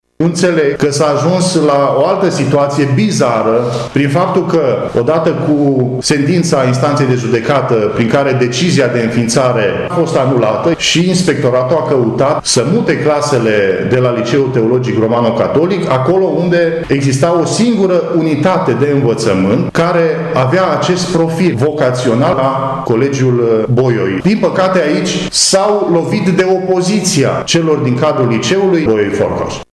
Într-o conferință de presă organizată astăzi, deputatul Marius Pașcan a atras atenția că situația nu e clară, pentru că Liceul „Bolyai” nu s-a pronunțat asupra preluării Liceului Catolic. Parlamentarul a amintit că, inițial, cei de la Bolyai au refuzat un asemenea transfer: